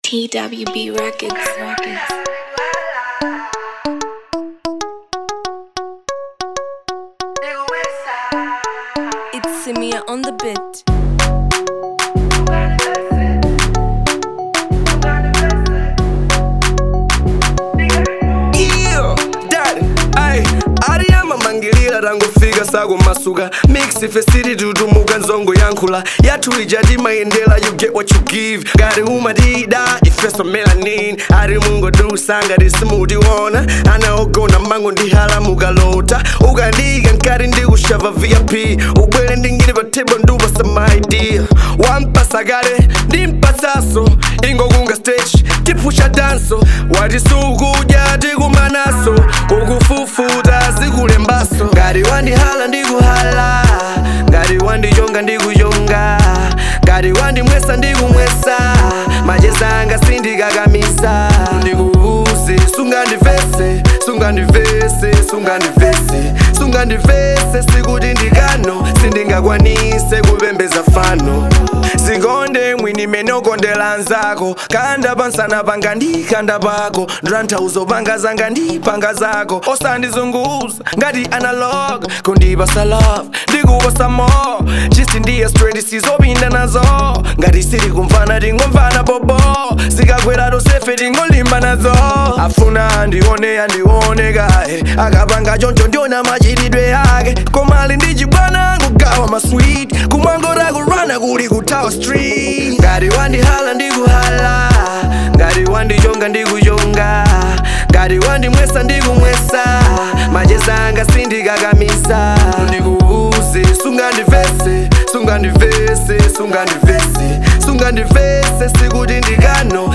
Genre : Afro Dancehall